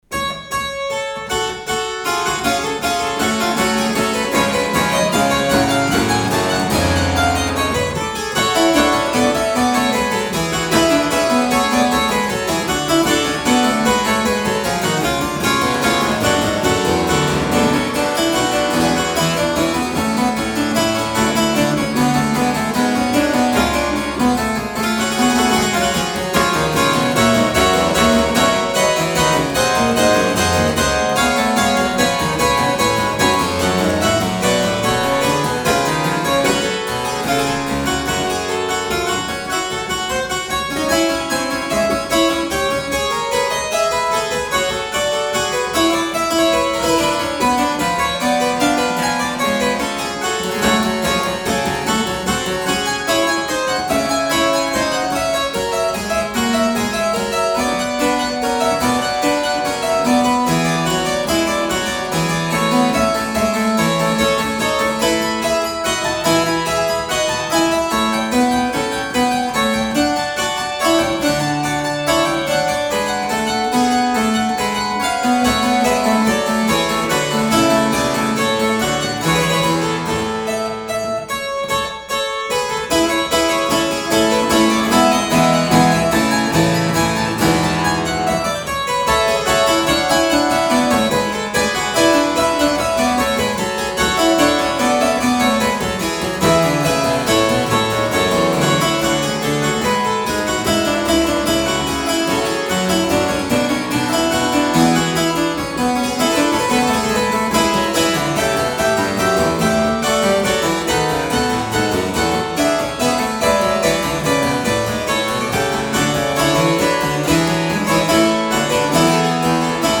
These are warts-and-all performances. Apart from *anything else*, the quilling on the Ruckers copy needs serious attention (even more than before).